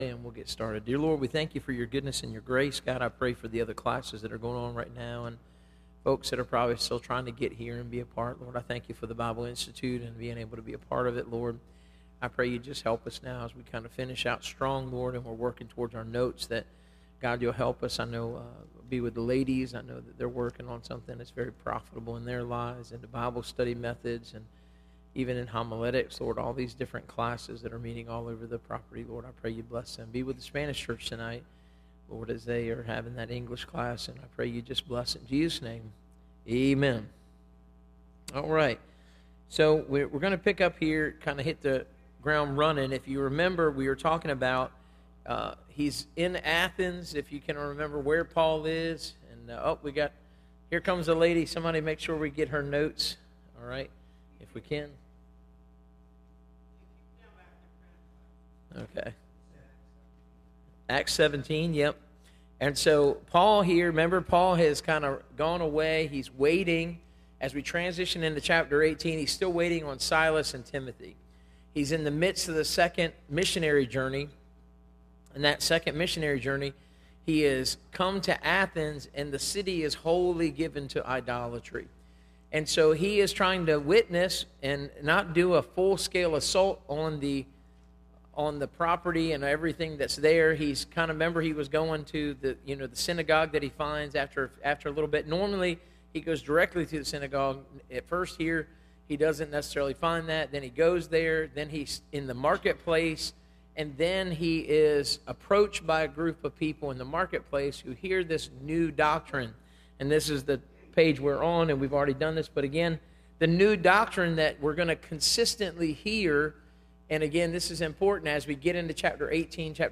Service Type: Institute